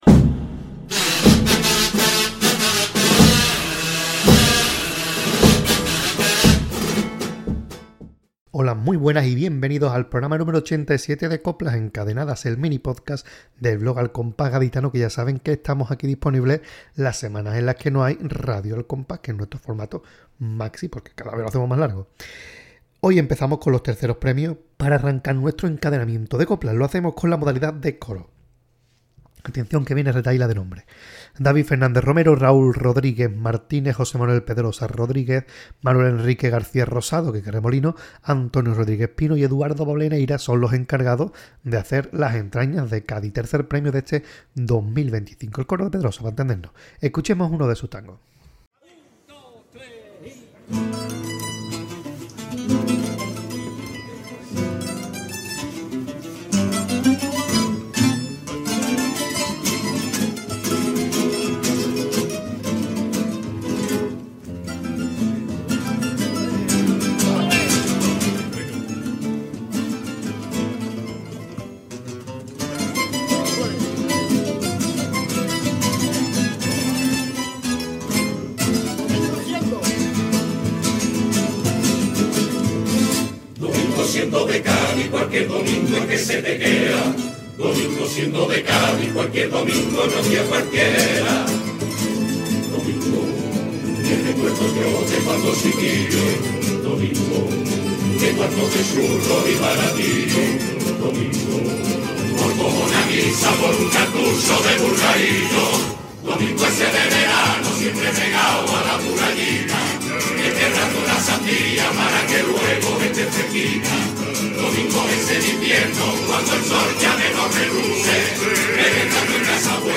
Tango
Pasodoble